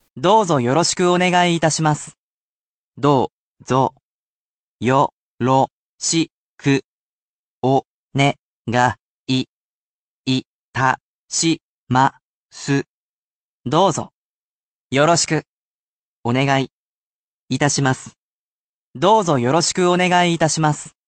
Every Japanese Word of the Weekday will not simply be a definition and vocabulary word, but also its romanisation along with an audio pronunciation.